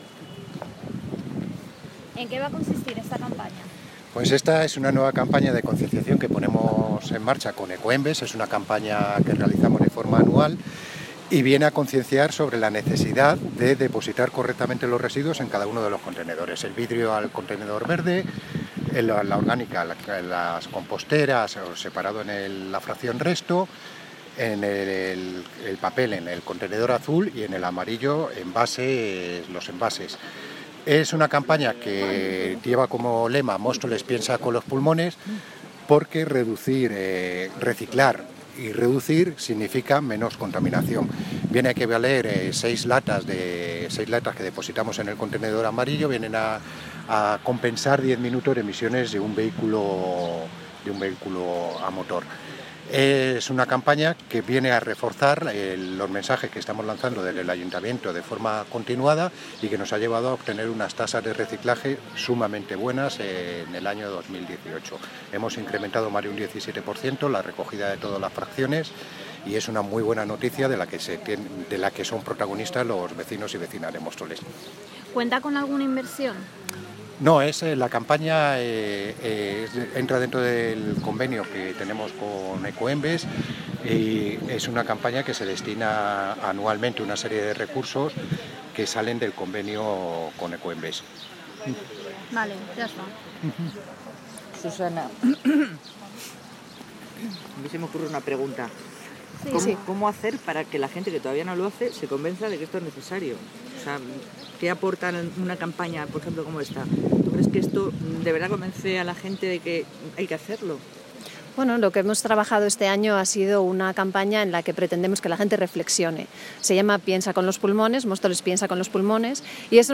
Audio - Miguel Ángel Ortega (Concejal de Medio Ambiente ) Sobre Móstoles, Piensa con los Pulmones